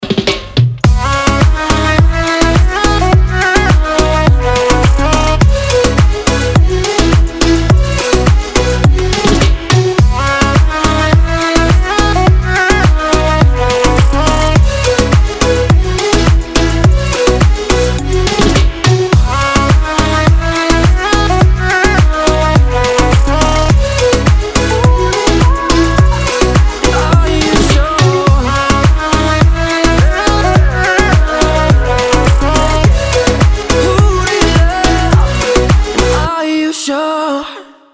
• Качество: 256, Stereo
поп
dancehall
dance
Electronic